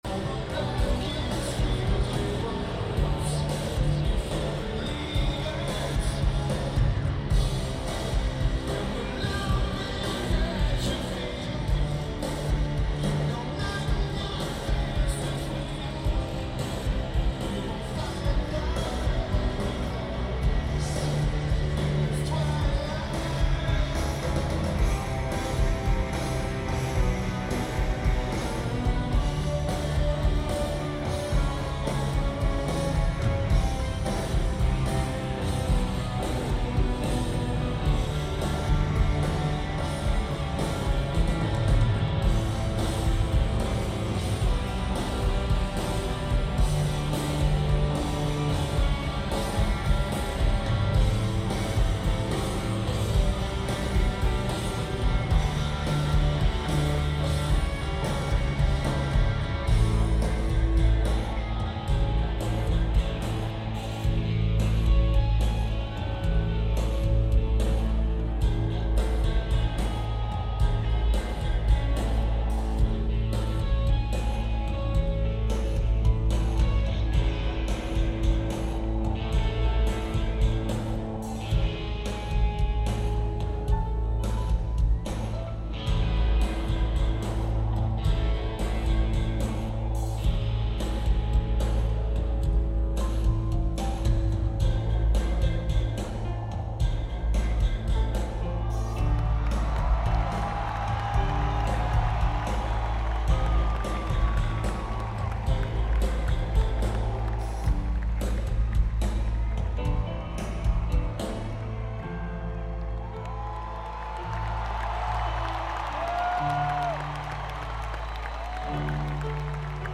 Lineage: Audio - AUD (CA-11 + BB + Edirol R09)